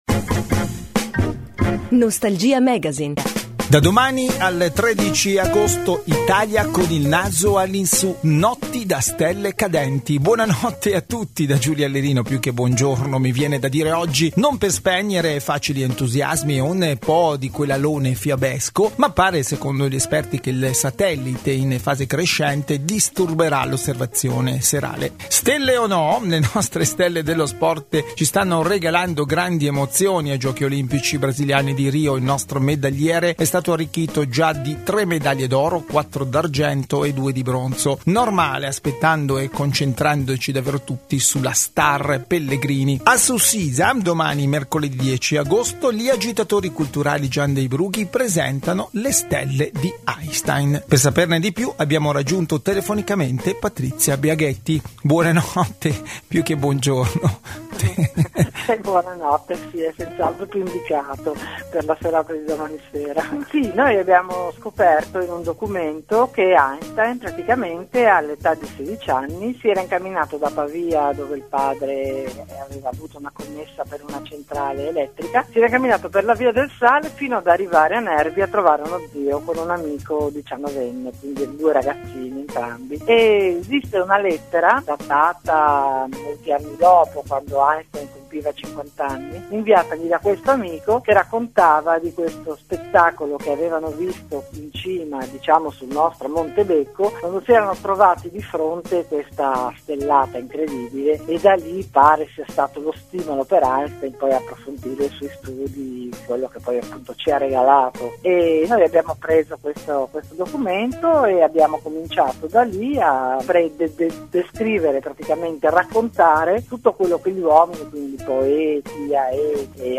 Intervista
andata in onda martedì 9 agosto.